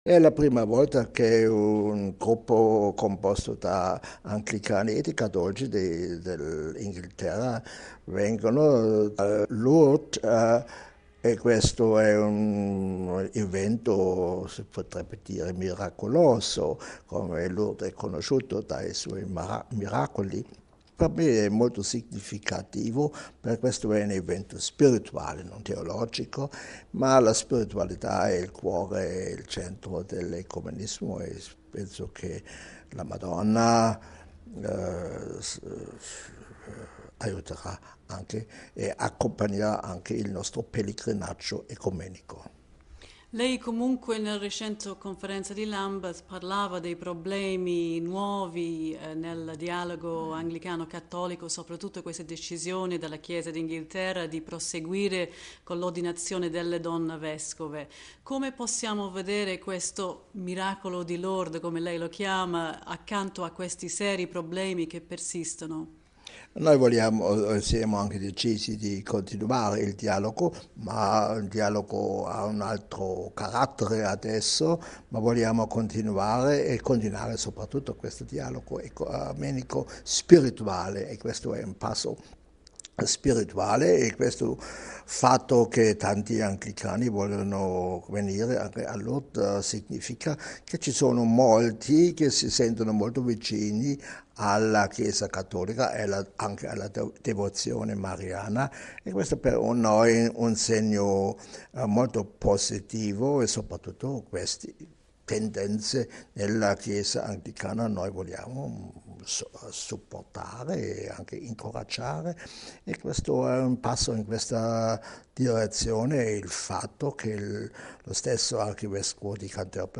ha intervistato il porporato su questa significativa esperienza ecumenica: